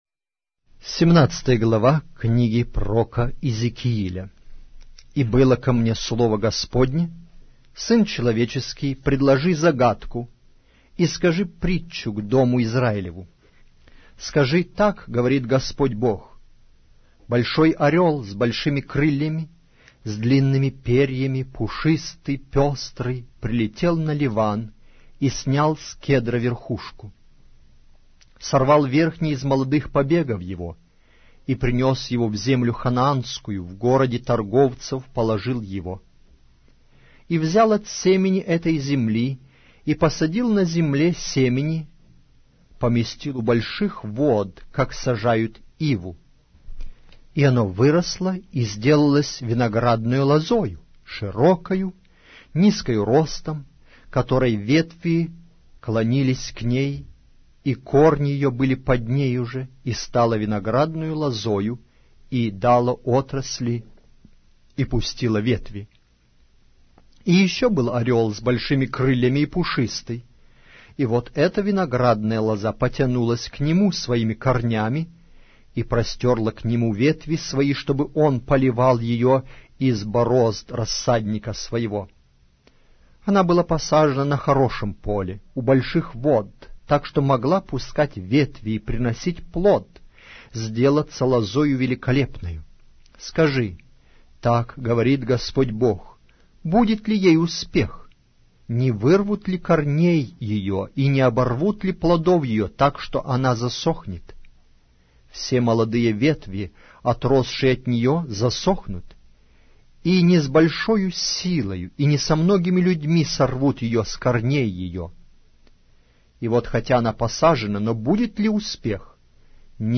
Аудиокнига: Пророк Иезекииль